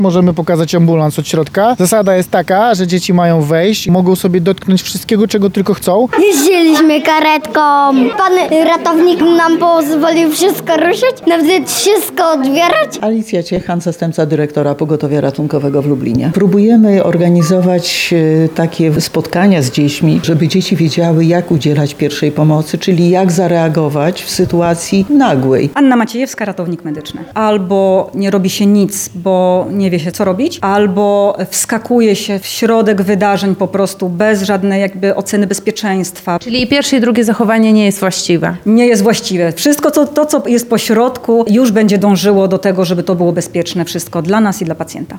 Tajniki pracy ratownika medycznego, wyposażenie ratowników, a także podstawowe zasady udzielania pierwszej pomocy medycznej poznawali najmłodsi mieszkańcy Lubelszczyzny. W Ośrodku Szkoleniowym Wojewódzkiego Pogotowia Ratunkowego w środę (21.05) odbył się dzień otwarty.